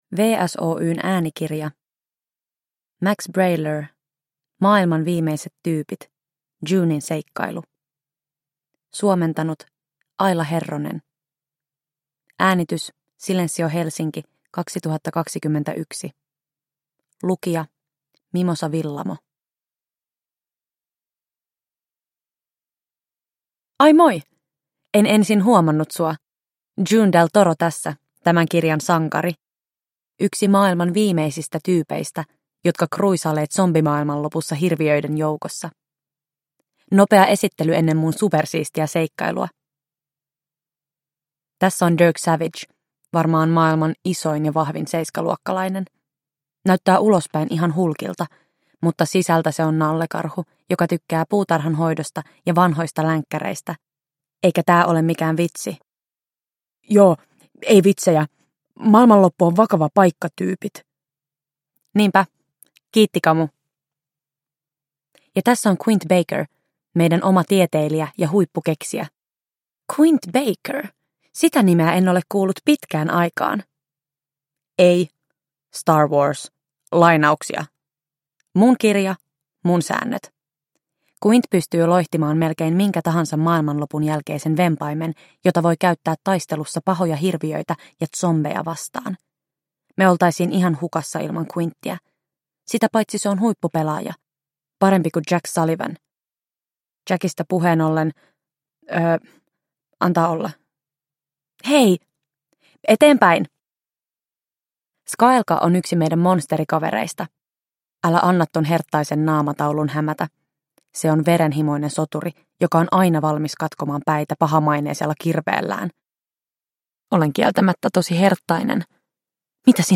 Uppläsare: Mimosa Willamo